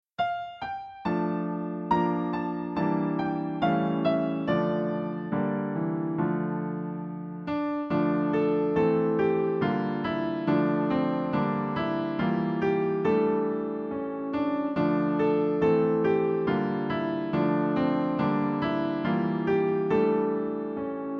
Piano Solo
Downloadable Instrumental Track